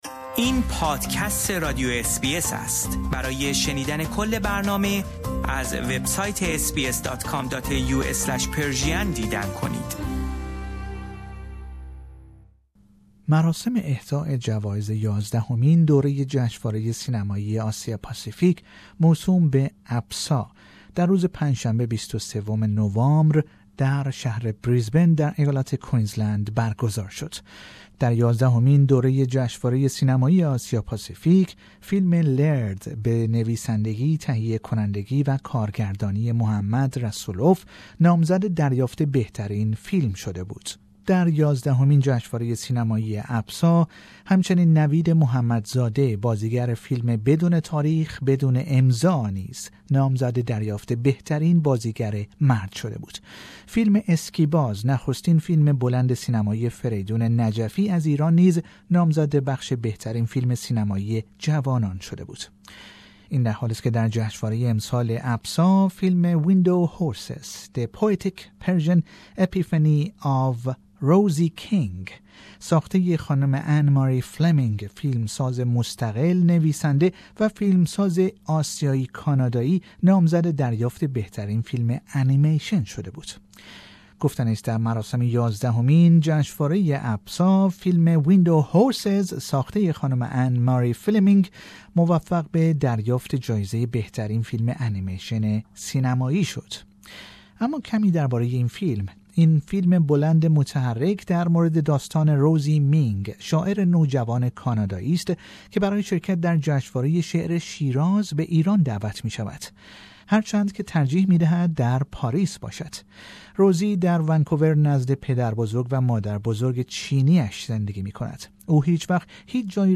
(این گفتگو که به زبان انگلیسی انجام شده به فارسی برگردانده شده است).